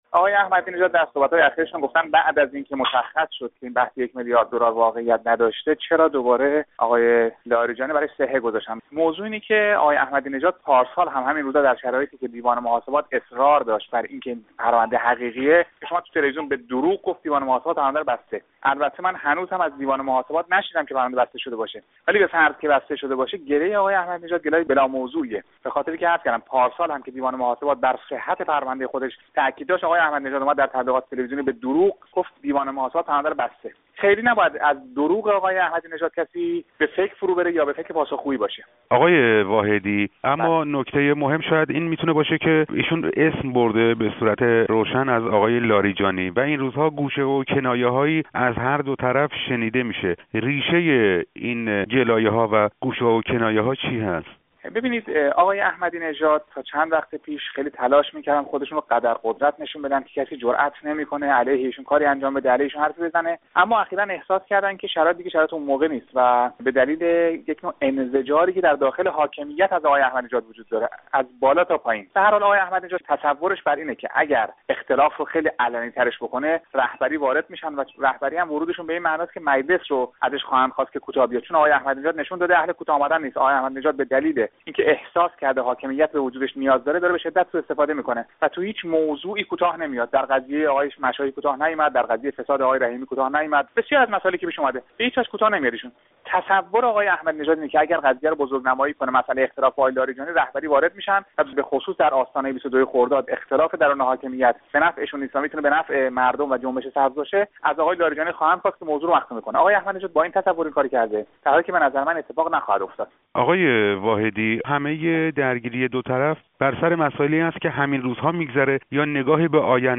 گفت و گوی